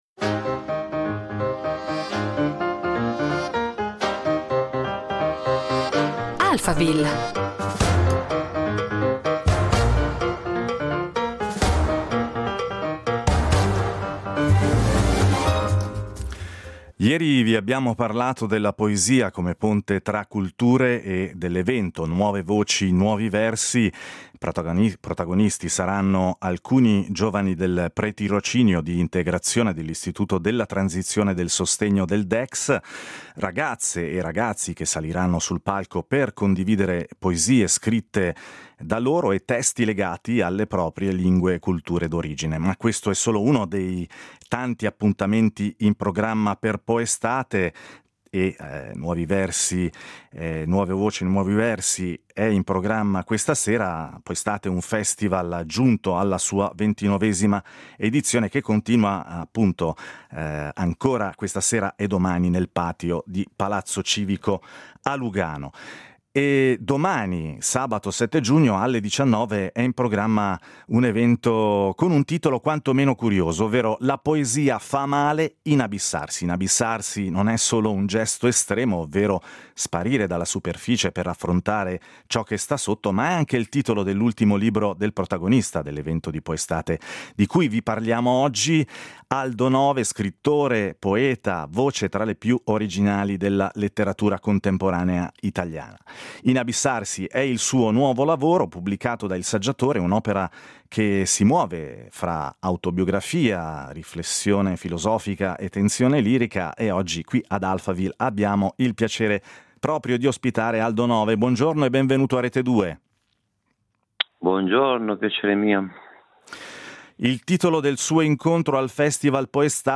Ne abbiamo parlato oggi ad Alphaville con lo stesso Aldo Nove che con il suo ultimo libro, Inabissarsi, pubblicato da Il Saggiatore, torna alla poesia.